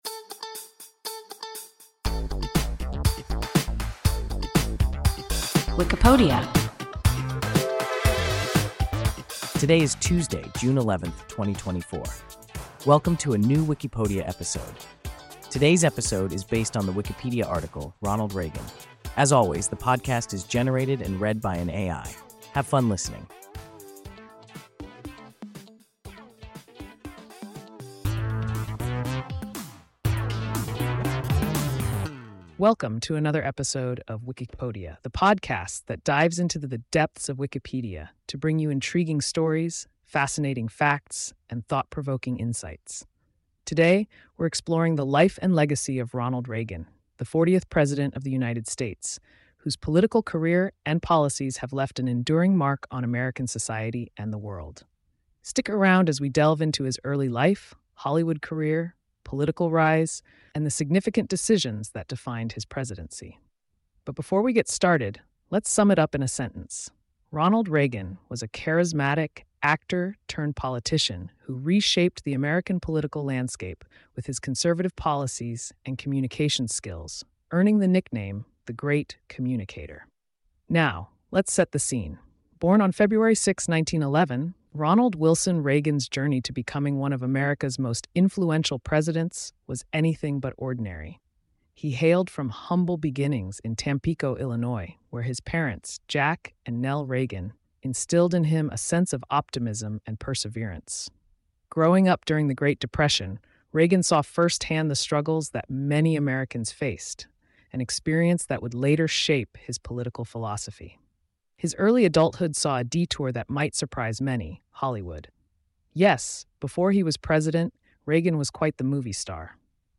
Ronald Reagan – WIKIPODIA – ein KI Podcast